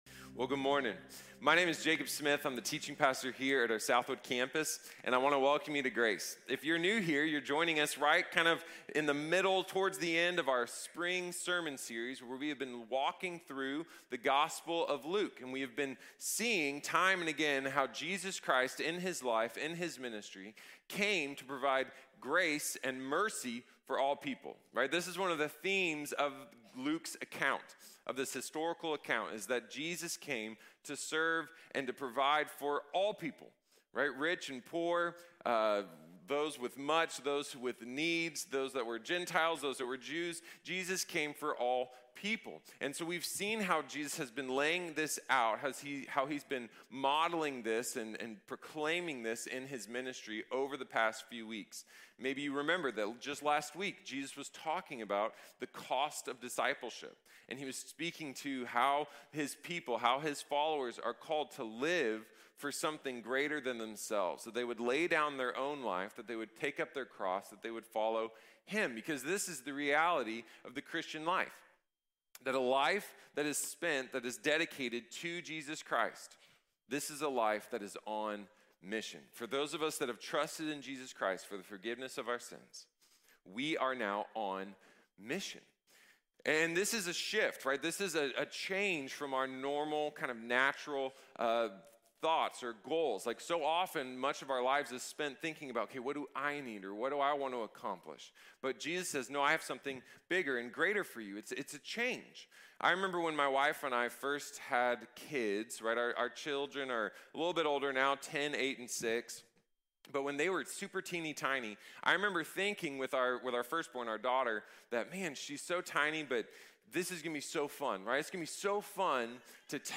The Requirements of the Mission | Sermon | Grace Bible Church